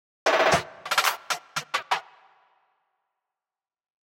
Mamacita Snare Roll.wav